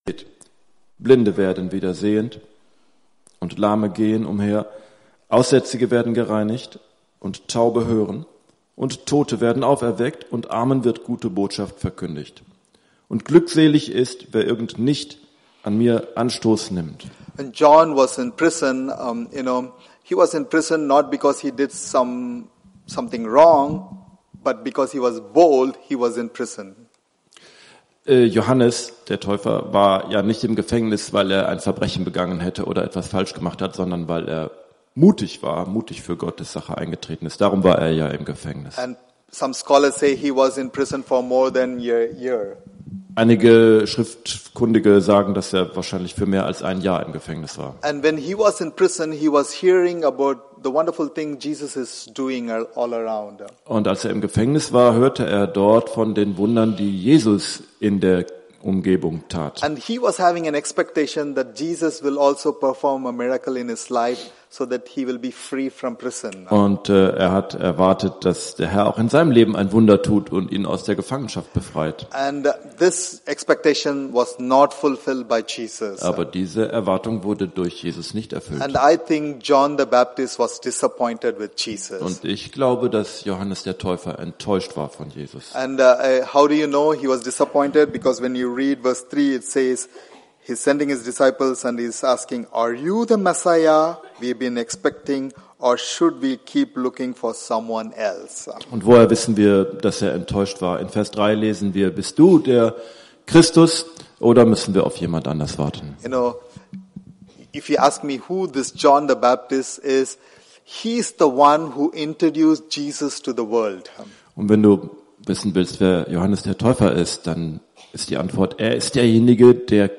Predigten 2022 - Gemeinde am Mühlenweg